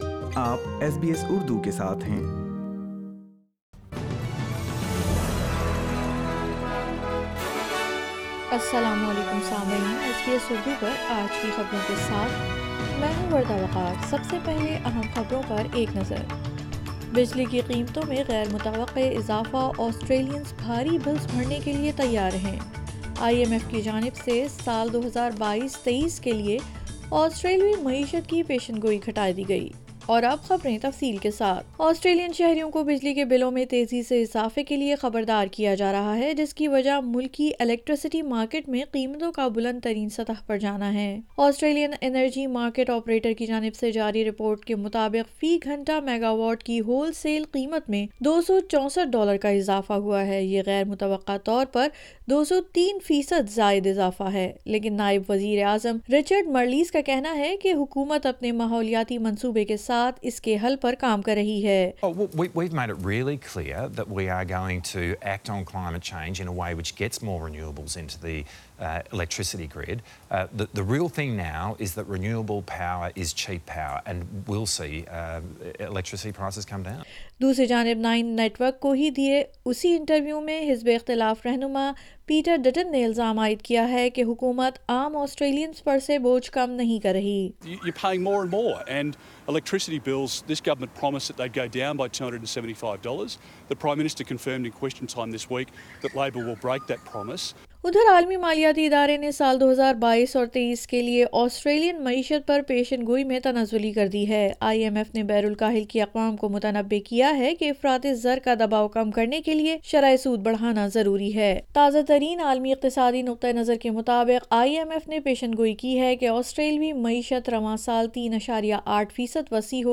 SBS Urdu News 29 July 2022